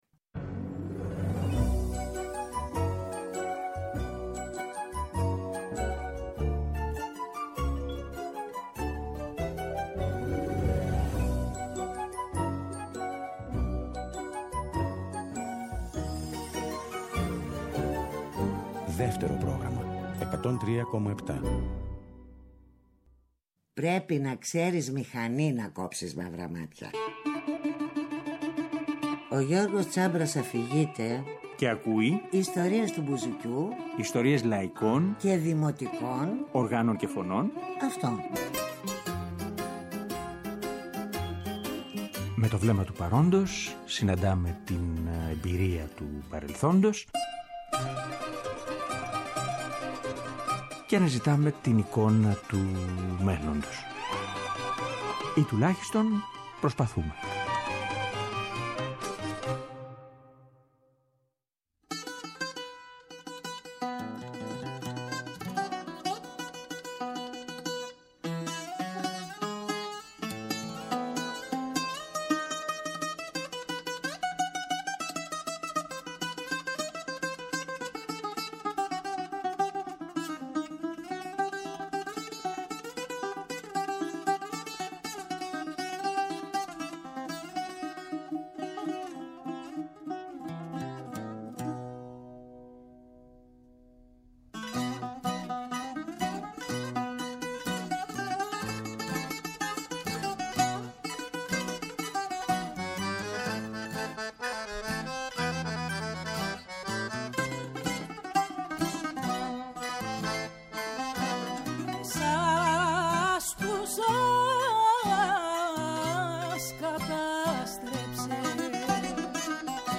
Αυτό το βαρύ αλλά εν τέλει, αισιόδοξο τραγούδι